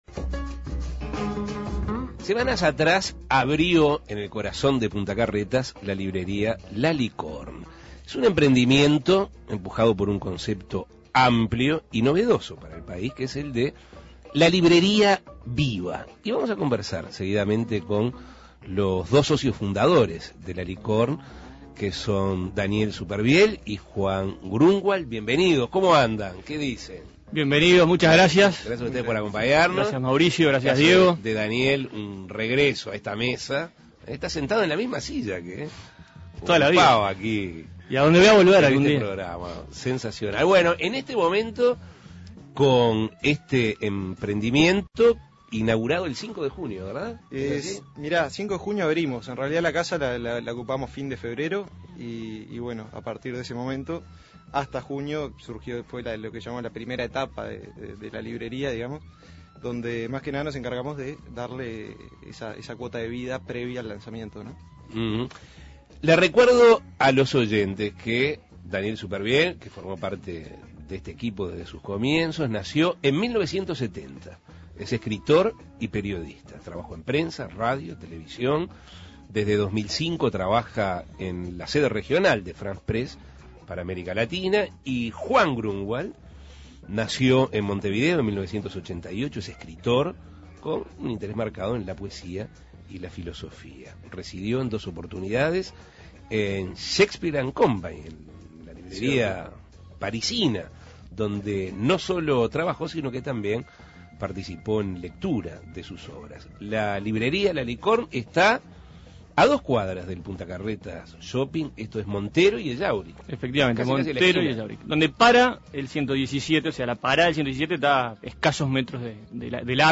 Entrevistas Una librería viva en el corazón de Punta Carretas Imprimir A- A A+ Semanas atrás abrió La Licorne, un emprendimiento empujado por un concepto amplio y novedoso para el país: el de "librería viva". Es por eso que Asuntos Pendientes conversó con dos de los fundadores